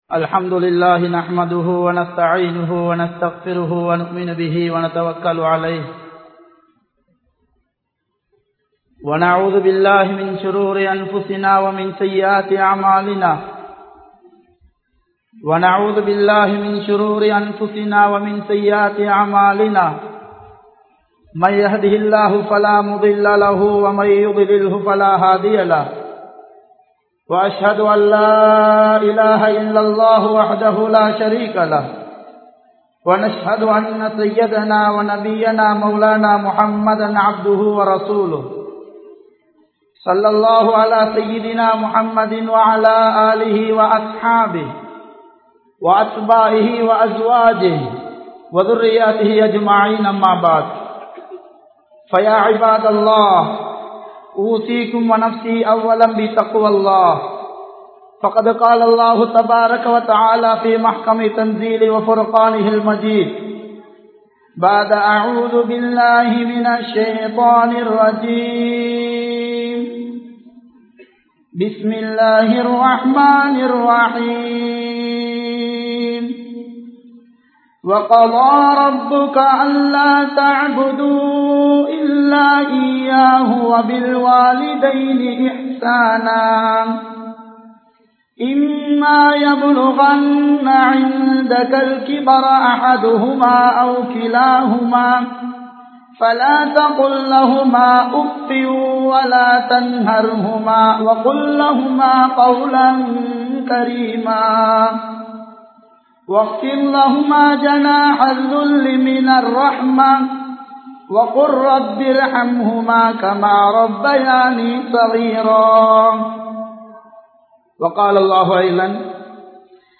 Pettroarin Sirappu (பெற்றோரின் சிறப்பு) | Audio Bayans | All Ceylon Muslim Youth Community | Addalaichenai